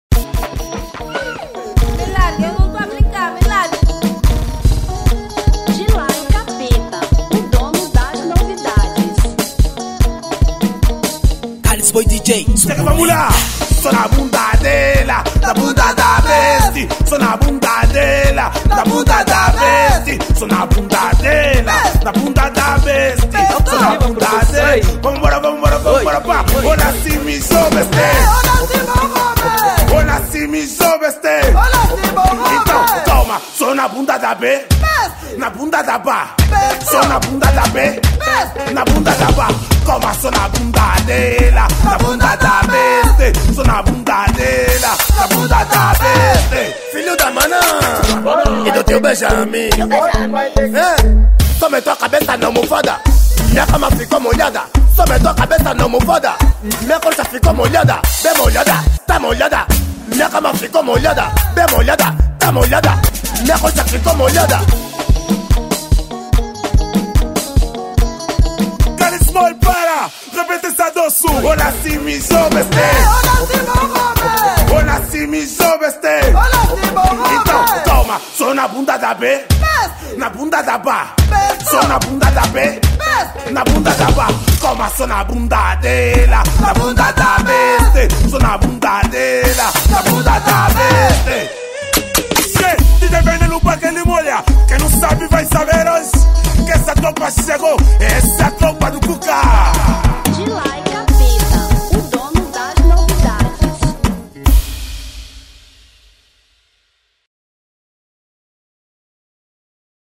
Afro House 2025